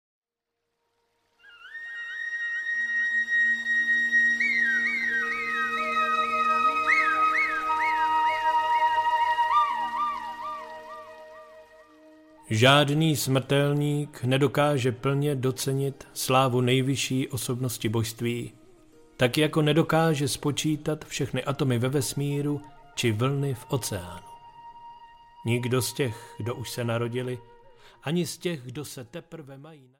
Krásný Bhágavatam díl 7. audiokniha
Ukázka z knihy